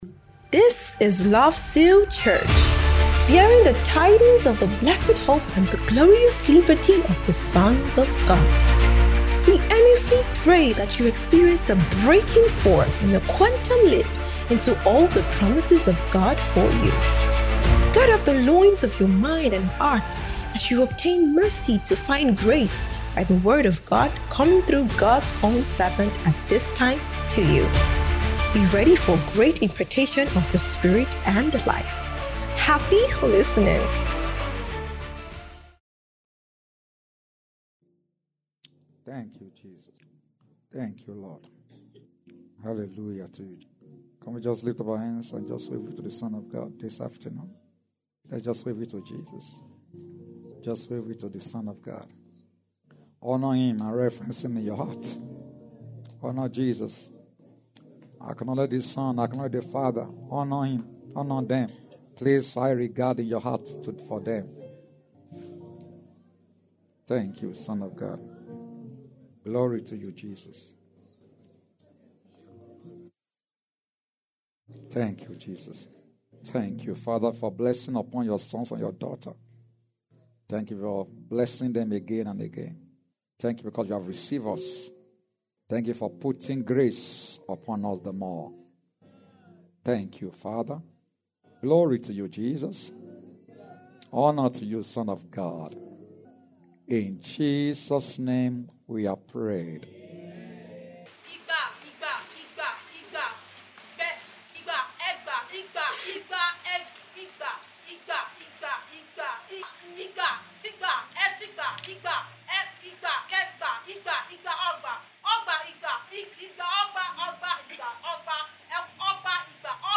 Special Christmas Season Service